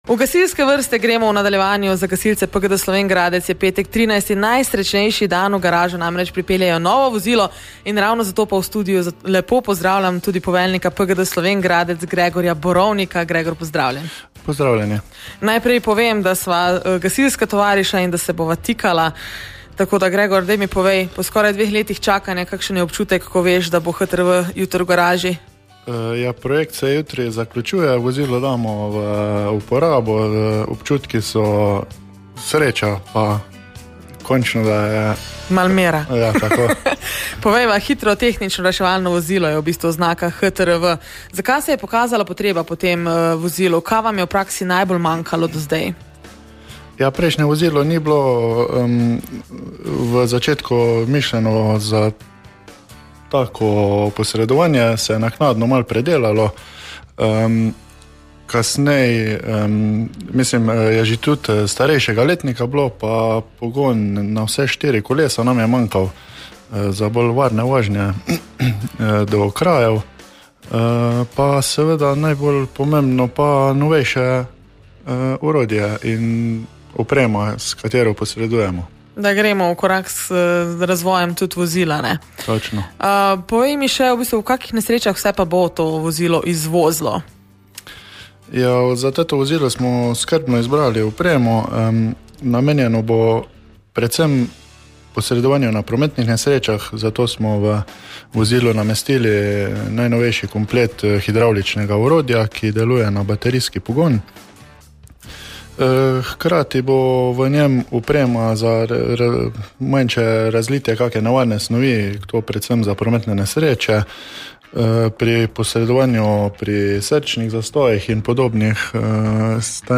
V pogovoru je pojasnil, zakaj je to vozilo ključno za hitrejša in varnejša posredovanja ter kako bo vplivalo na delo gasilcev na terenu. Če vas zanima, kaj pomeni sodobna oprema v praksi in zakaj je ta pridobitev tako pomembna za varnost v Slovenj Gradcu in širše, prisluhnite pogovoru.